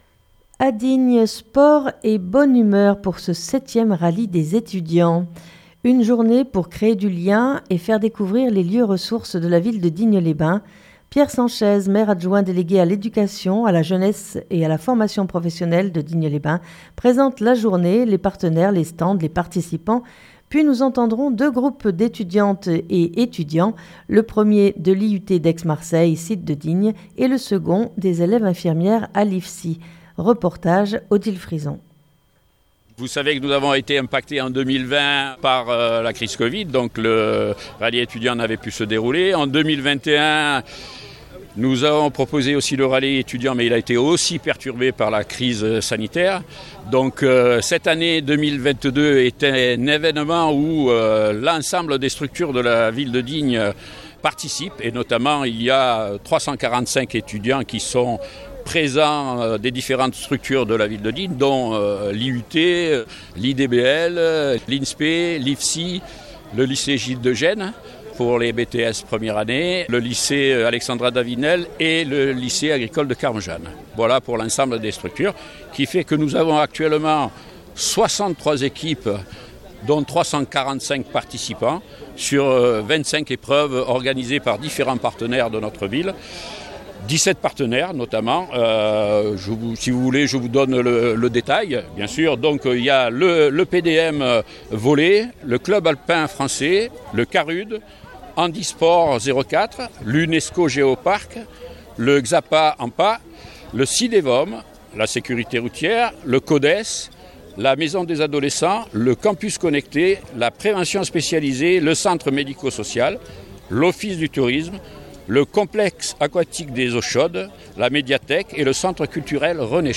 Une journée pour créer du lien, et faire découvrir les lieux ressources de la ville de Digne les bains. Pierre Sanchez maire adjoint délégué à l'éducation à la jeunesse et à la formation professionnelle de Digne les bains présente la journée, les partenaires, les stands, les participants, puis nous entendrons 2 groupes d'étudiantes, et étudiants, le 1er de l'IUT d'Aix-Marseille site de Digne, et le second, des élèves infirmières à l'IFSI. Reportage